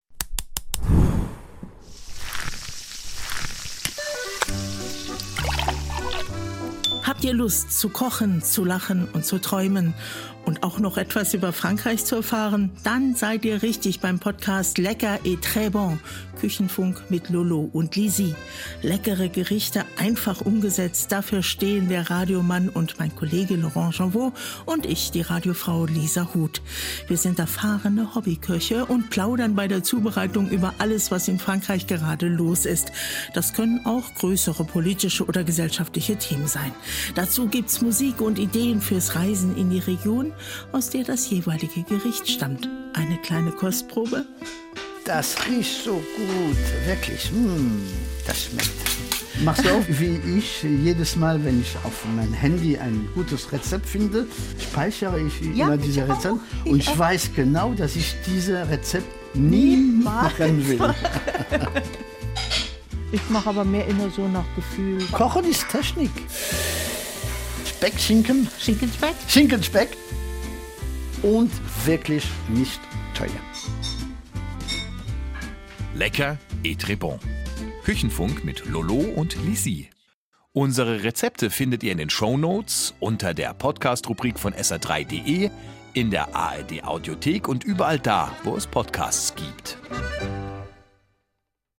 Die erfahrenen Hobbyköche plaudern bei der Zubereitung über alles, was in Frankreich gerade los ist.